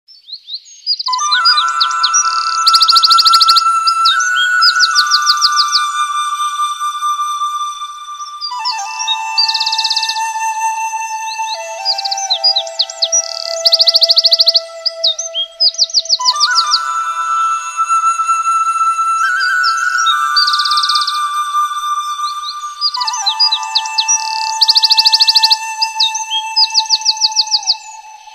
File Name: Birds.mp3